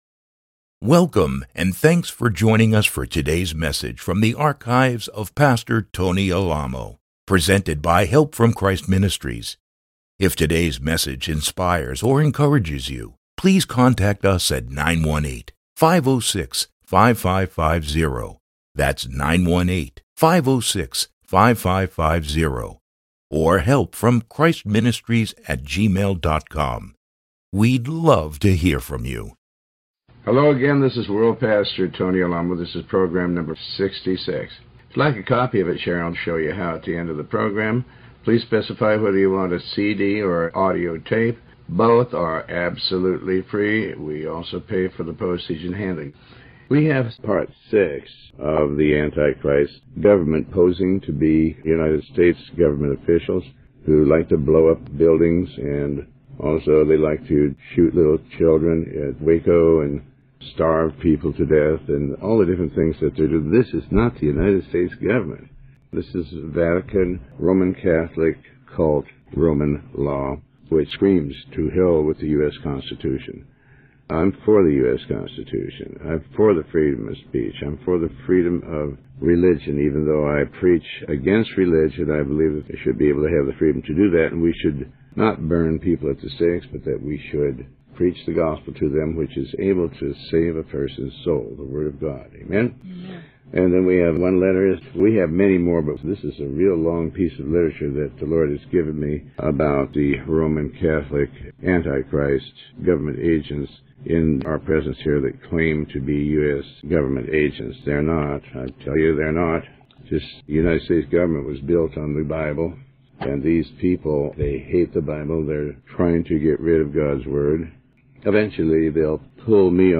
Sermon 66A